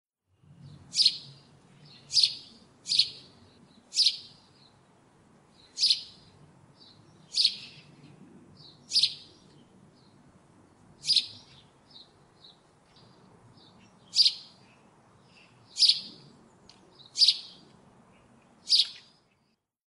Stimme Haussperling
Haussperling.mp3